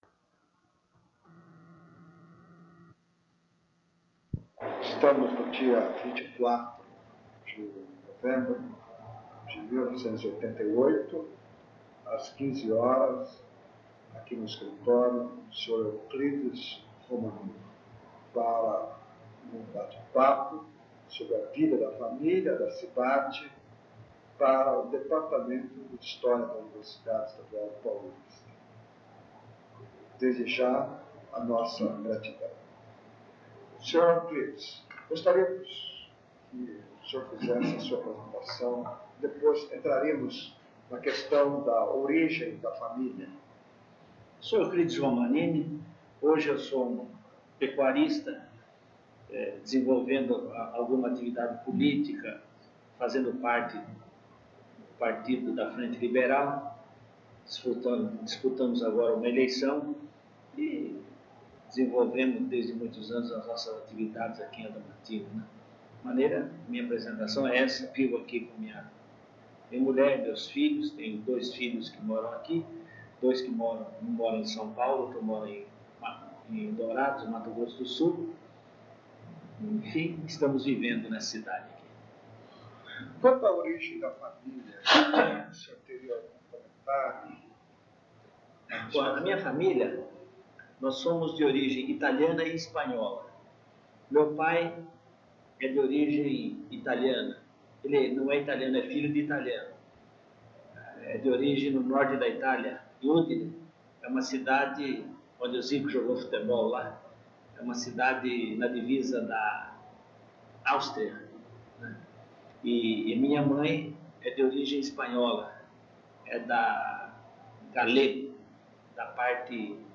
Entrevista
*Recomendado ouvir utilizando fones de ouvido.